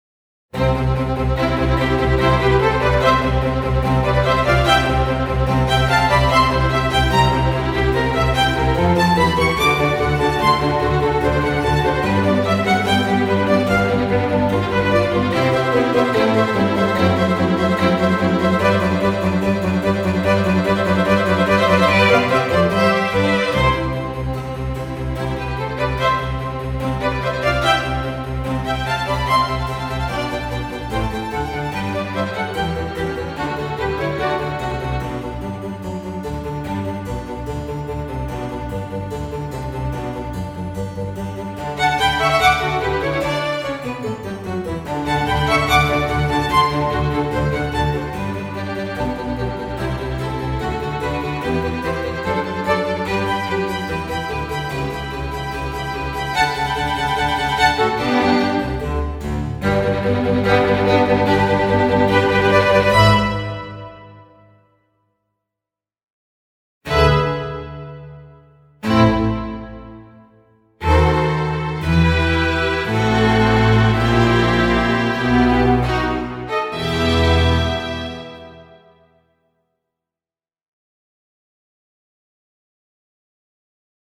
Vary the velocity - no following notes may have the same velocity than the one before.
And here is the result of varying the velocities in each section Violins, Violas, Basses..
There are some parts which I wasn't able to suppress the "machine" but viewing the whole piece: It got a lot of life and musicality just with this variation of the velocity.